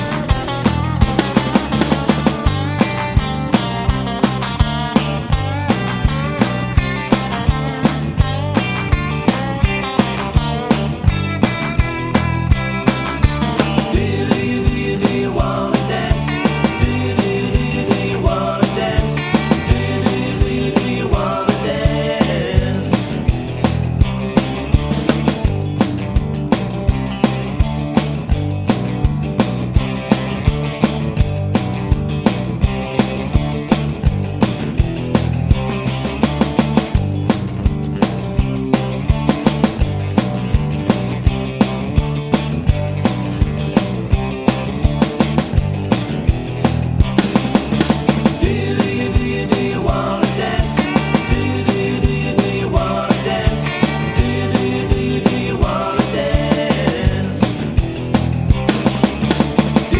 With Intro Chord Pop (1960s) 2:19 Buy £1.50